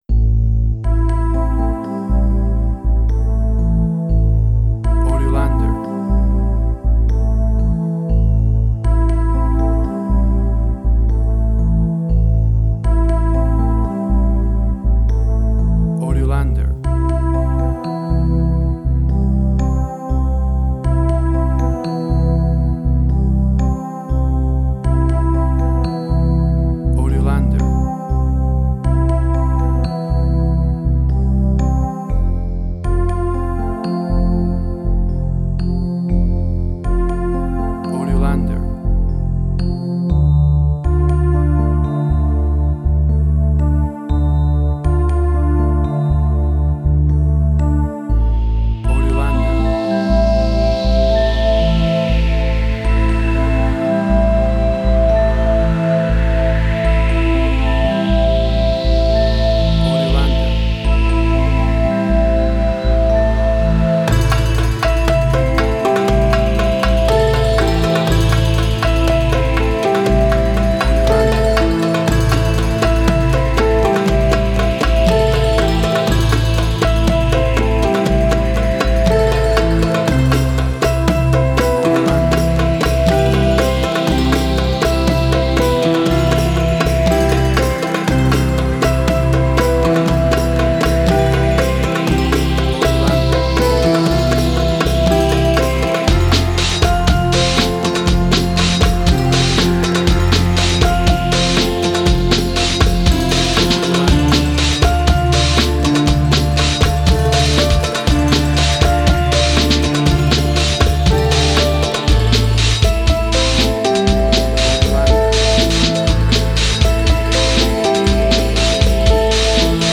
emotional music
Tempo (BPM): 60